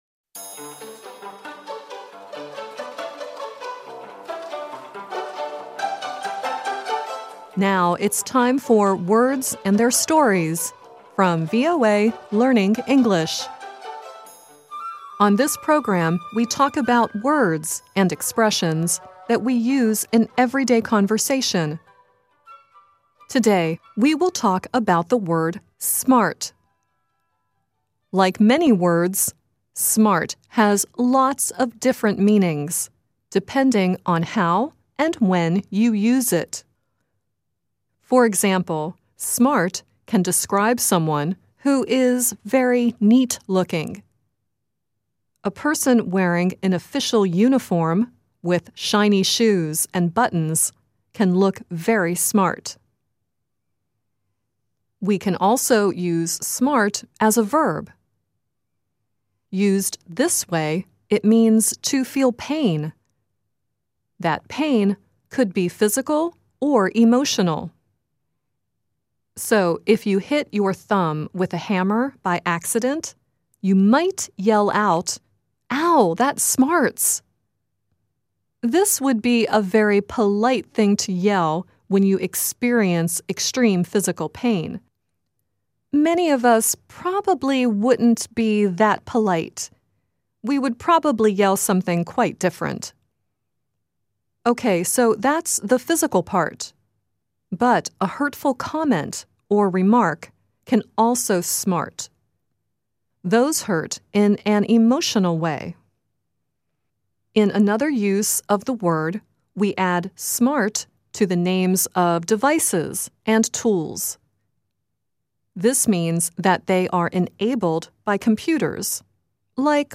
The song at the end of the program is Sarah Slean singing “Book Smart, Street Stupid.”